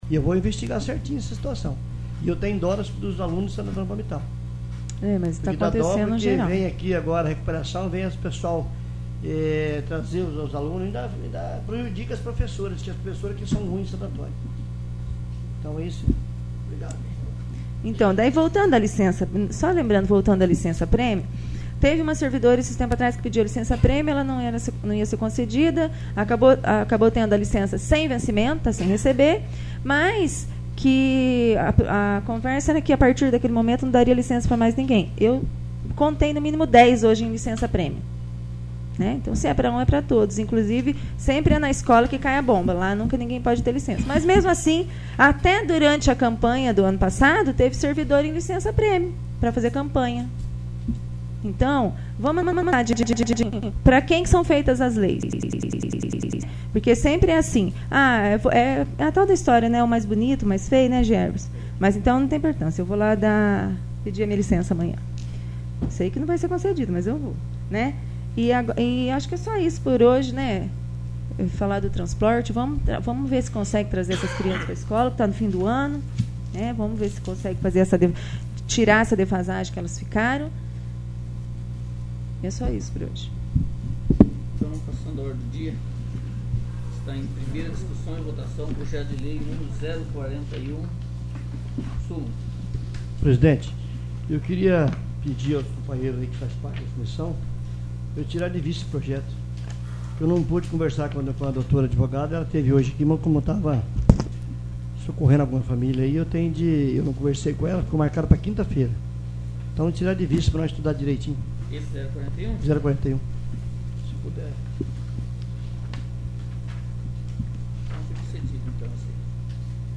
33º. Sessão Ordinária